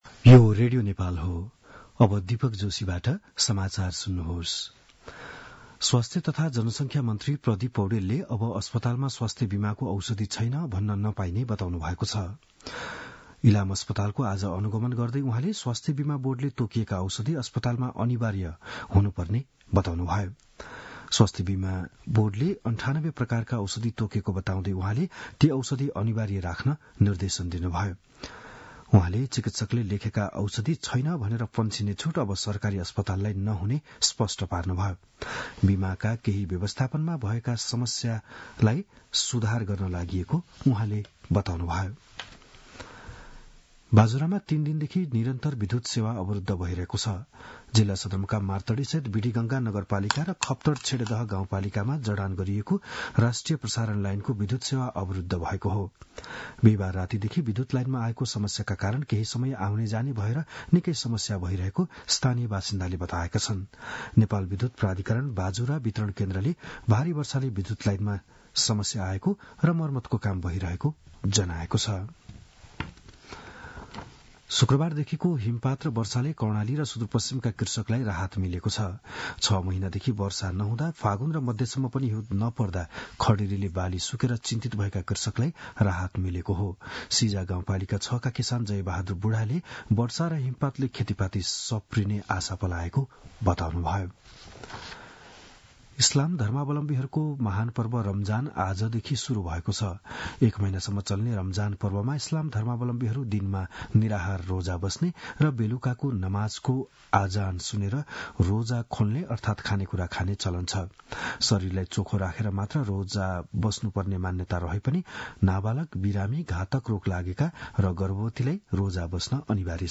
बिहान १० बजेको नेपाली समाचार : १९ फागुन , २०८१